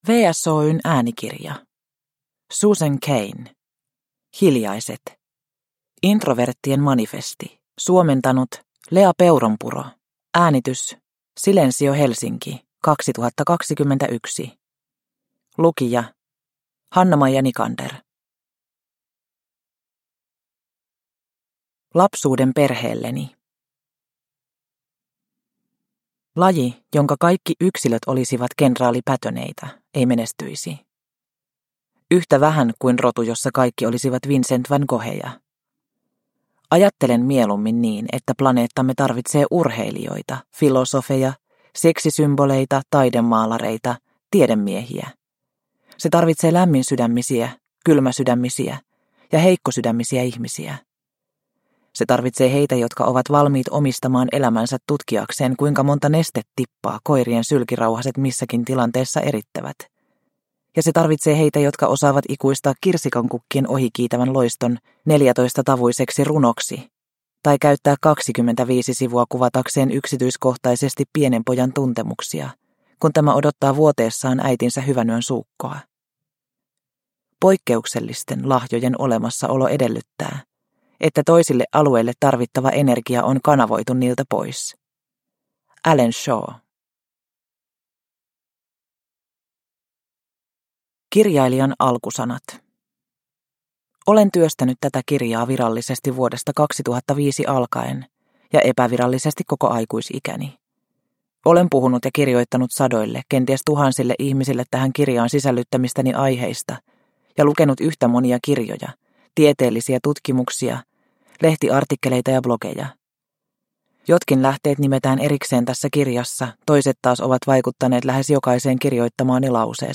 Hiljaiset – Ljudbok – Laddas ner